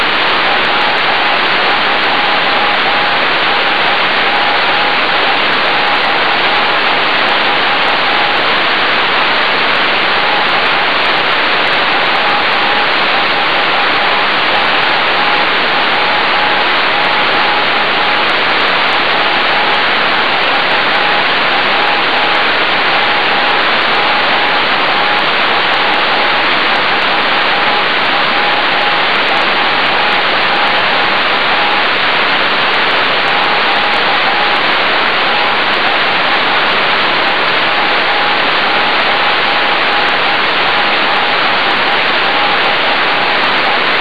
CW keying is through FSK'ing the 10MHz OCXOs.
241GHz QSO Audio
an excerpt from the 61.8km 241GHz QSO (44 sec, 948kB .WAV file).